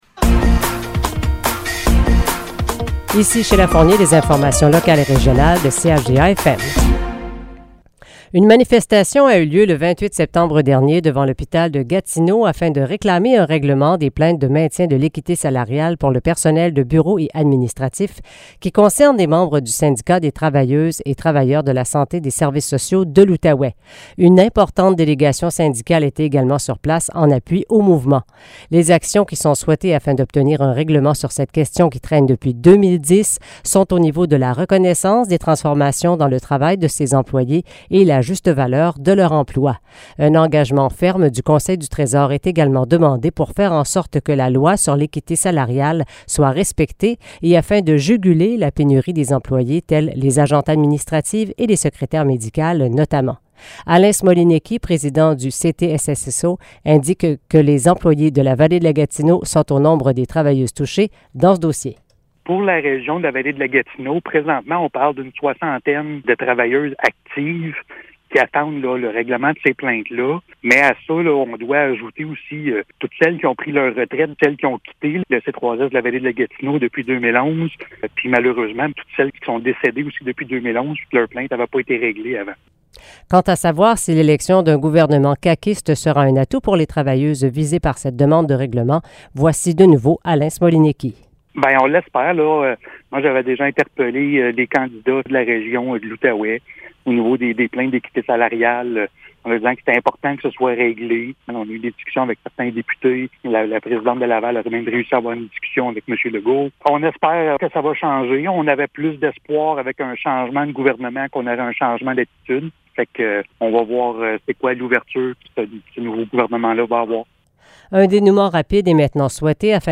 Nouvelles locales - 5 octobre 2022 - 12 h